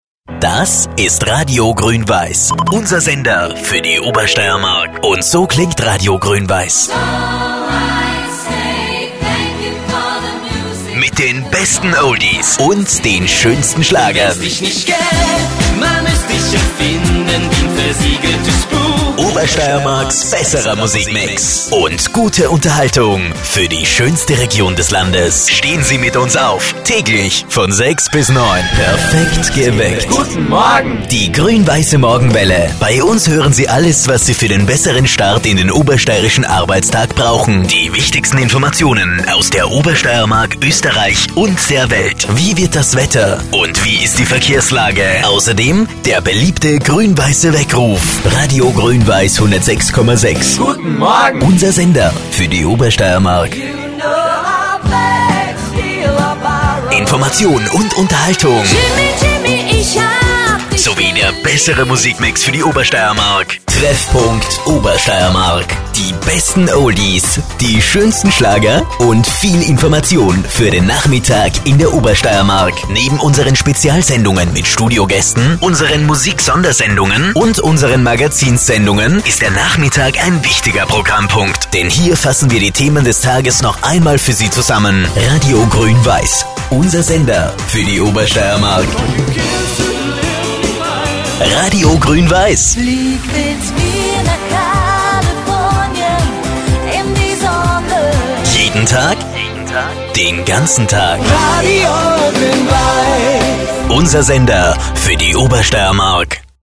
deutschsprachiger Sprecher.
Sprechprobe: Sonstiges (Muttersprache):
german voice over artist